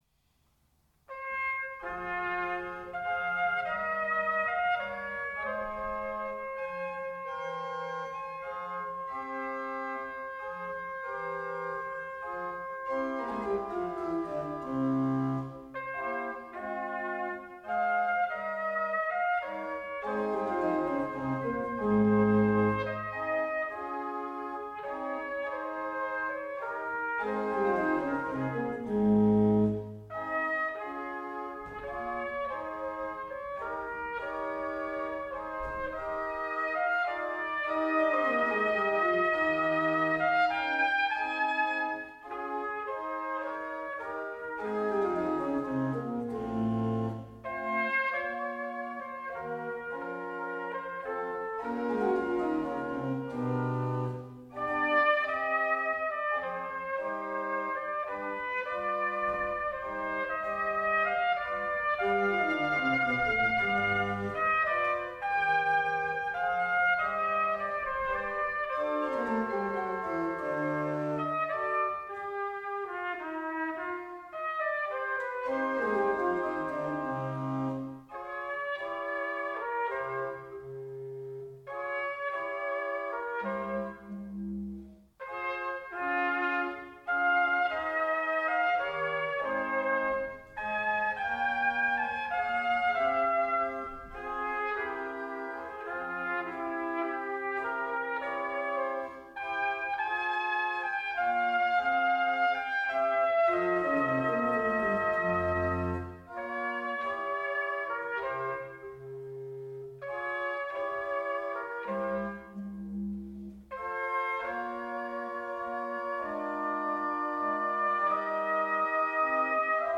bearbeitet für Orgel und Trompete.